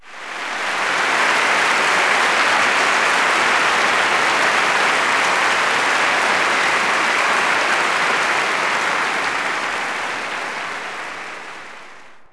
clap_043.wav